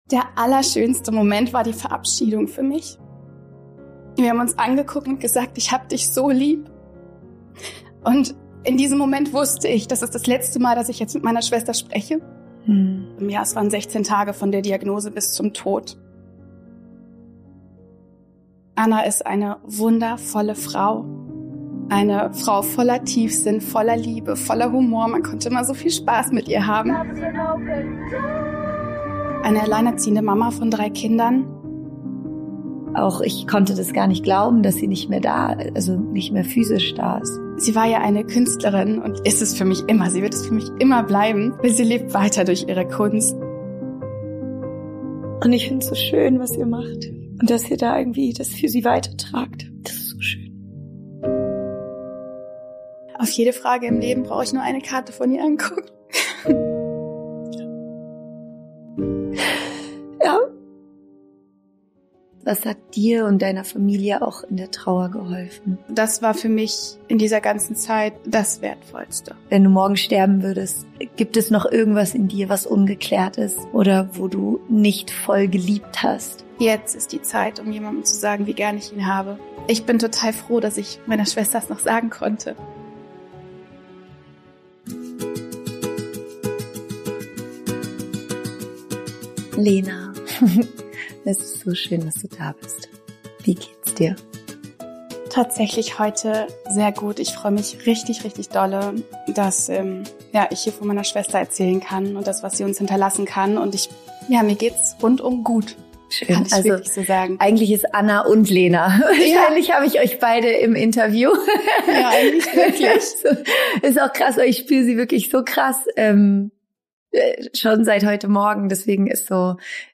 Dieses Gespräch ist wohl eines der emotionalsten, die ich je im Podcast führen durfte.